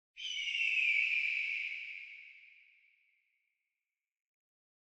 UI_Bird_VO.ogg